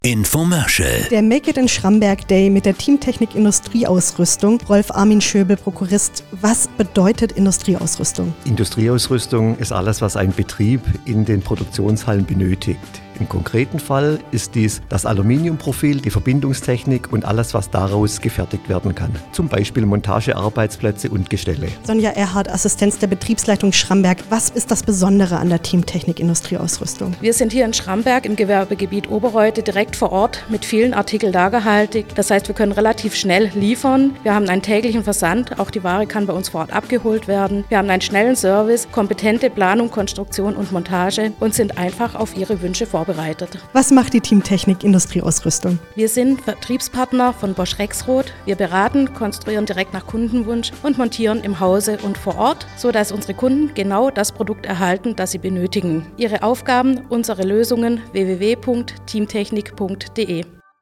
Infomercial - Make it in Schramberg _ Team Technik MP3.mp3